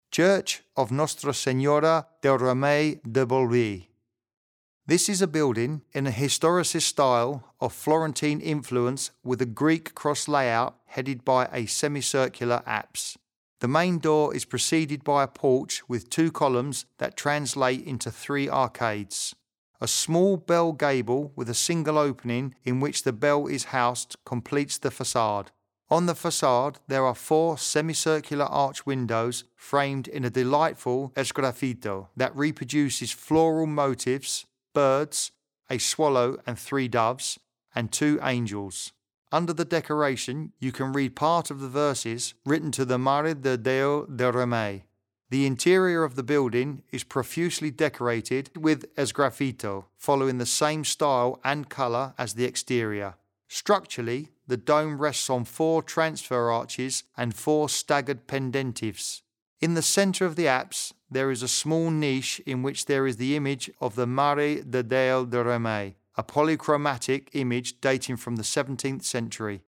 Escolta la locució en la que t’expliquem com és l’ermita de Nostra Senyora del Remei.